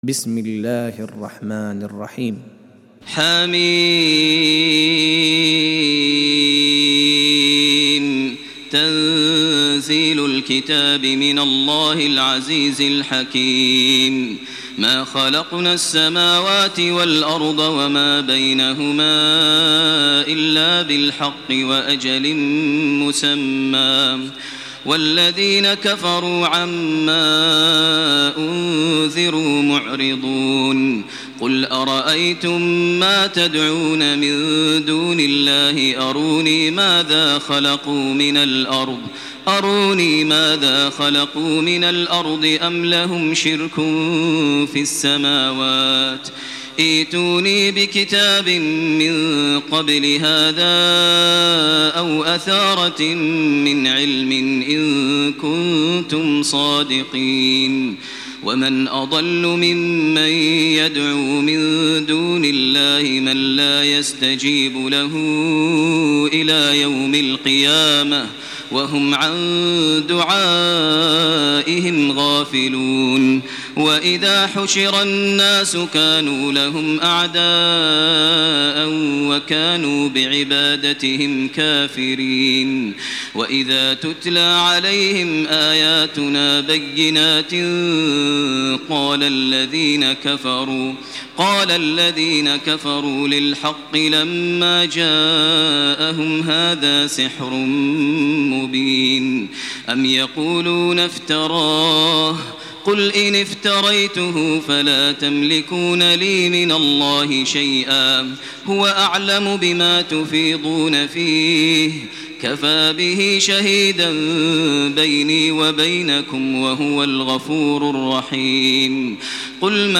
تراويح ليلة 25 رمضان 1428هـ من سور الأحقاف و محمد و الفتح (1-17) Taraweeh 25 st night Ramadan 1428H from Surah Al-Ahqaf and Muhammad and Al-Fath > تراويح الحرم المكي عام 1428 🕋 > التراويح - تلاوات الحرمين